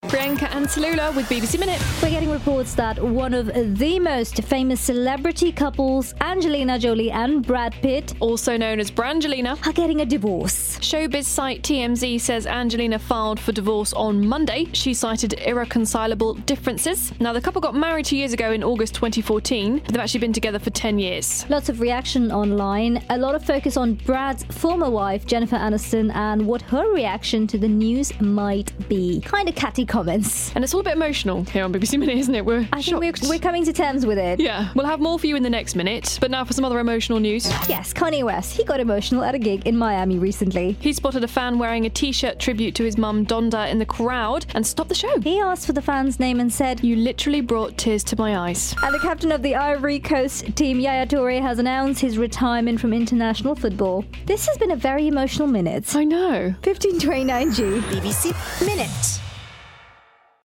Breaking news of Brad Pitt and Angelina Jolie divorce on BBC Minute.